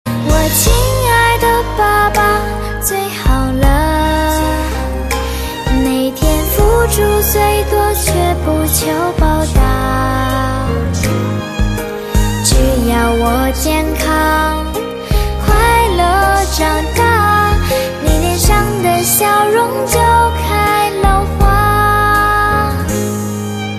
M4R铃声, MP3铃声, 华语歌曲 36 首发日期：2018-05-14 19:43 星期一